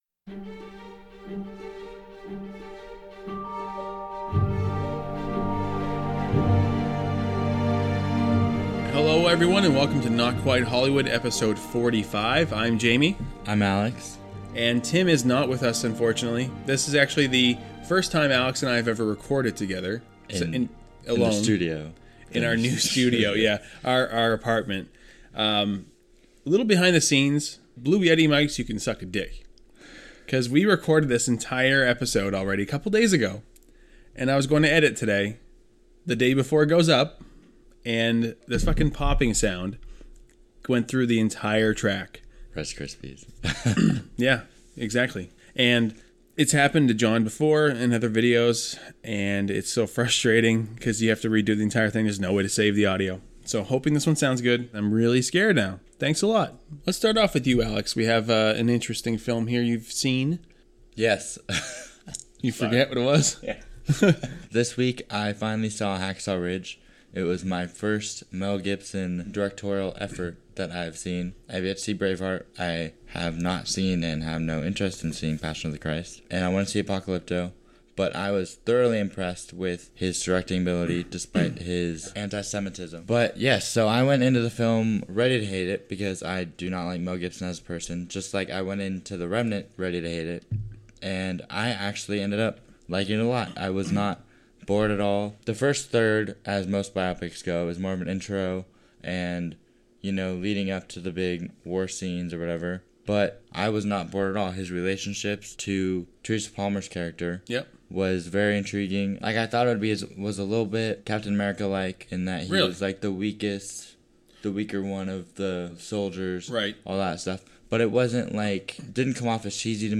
We do voices, talk shit on the prequels (you know which ones), chaotic editing in film, and so much more!